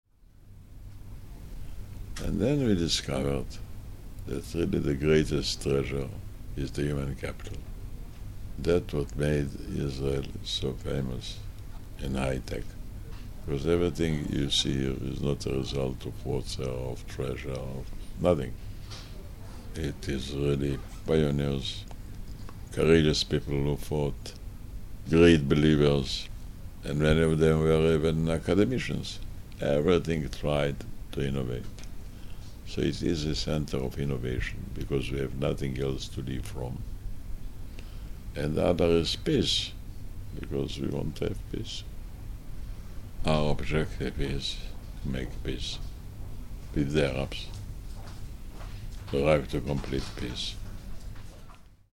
His speech was slow and the voice even lower than I (and my microphone) was prepared for.